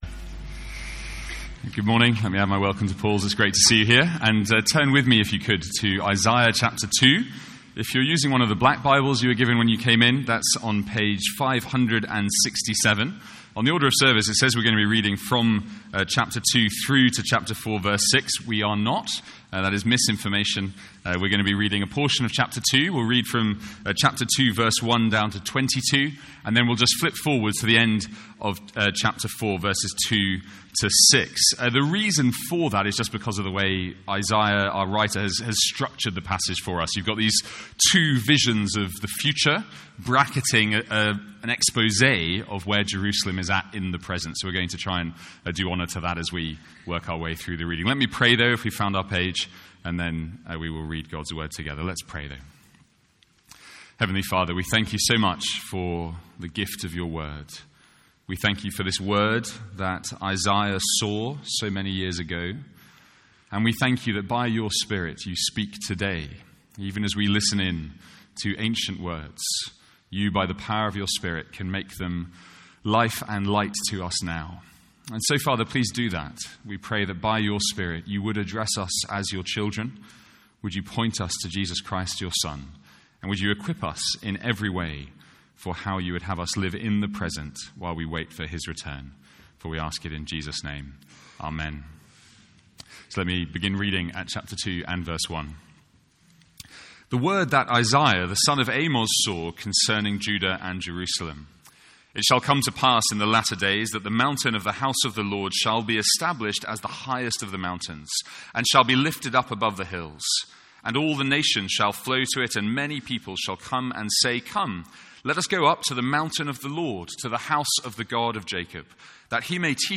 Sermons | St Andrews Free Church
From our morning service in Isaiah.